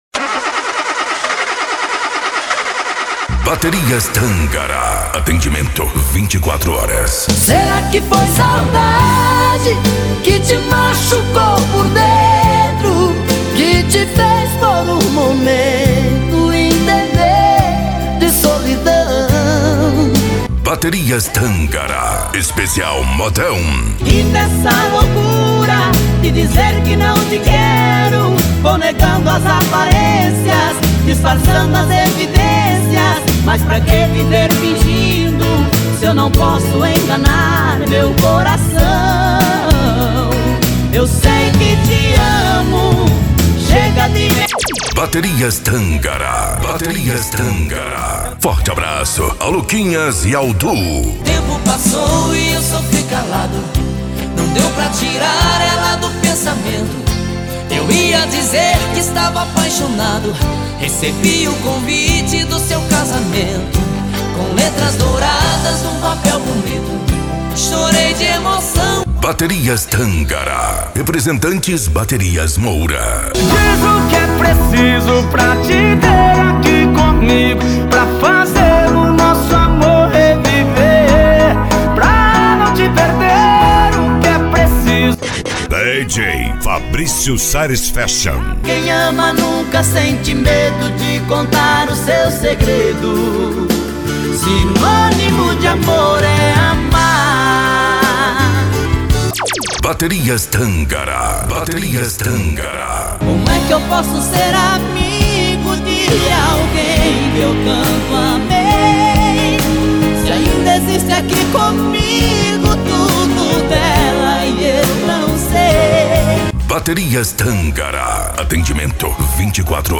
Modao